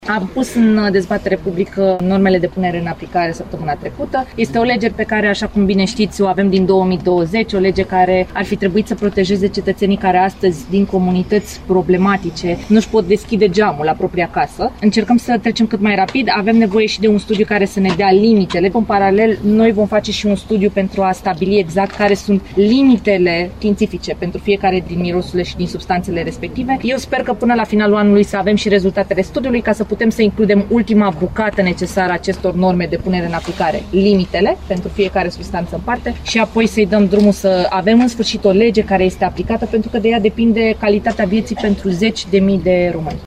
Prezentă la deschiderea primului Centru de Aport Voluntar din comuna timișeană Dumbrăvița, ministrul Diana Buzoianu a precizat că studiul este necesar pentru normele de aplicare a legii.